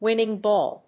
winning+ball.mp3